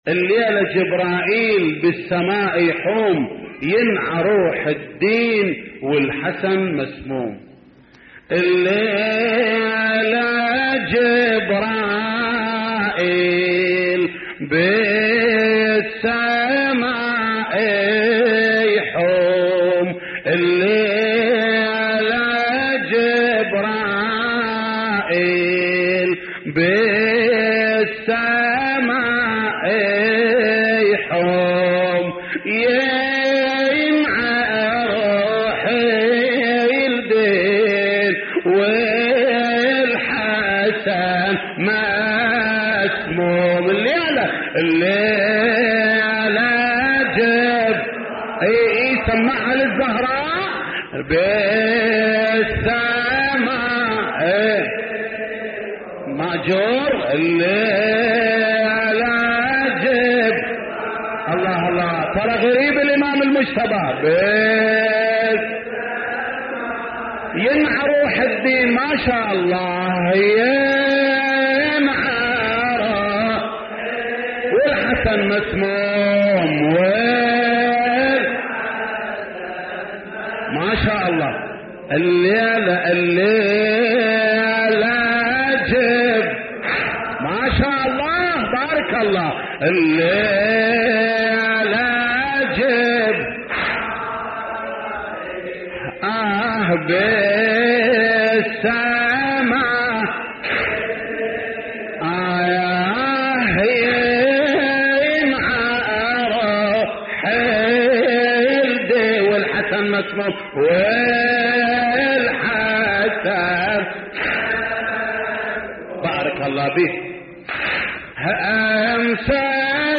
تحميل : الليلة جبرائل بالسماء يحوم ينعه روح الدين والحسن مسموم / الرادود جليل الكربلائي / اللطميات الحسينية / موقع يا حسين